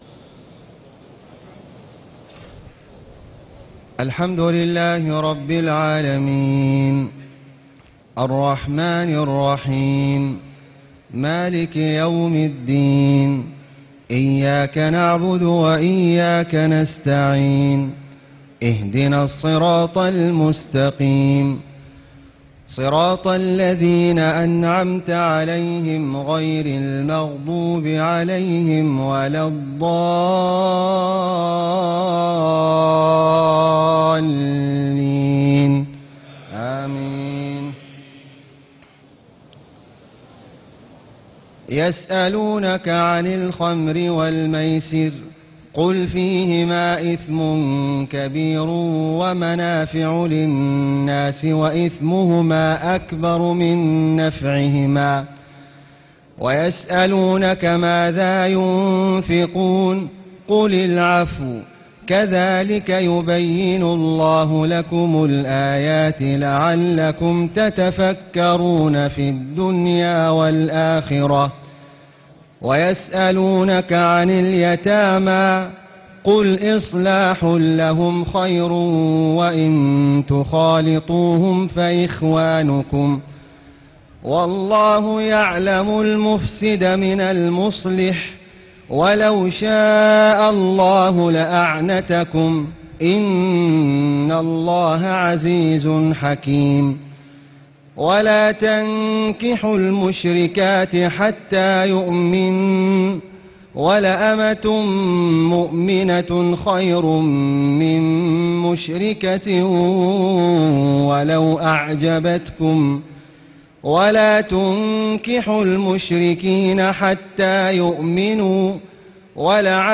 تهجد ليلة 22 رمضان 1436هـ من سورة البقرة (219-252) Tahajjud 22 st night Ramadan 1436H from Surah Al-Baqara > تراويح الحرم المكي عام 1436 🕋 > التراويح - تلاوات الحرمين